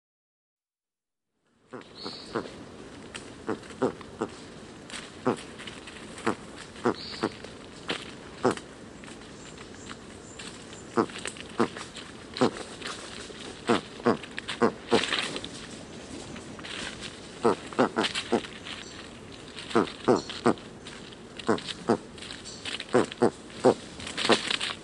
1. Host animal: Bush Turkey
AustralianBrushturkey.mp3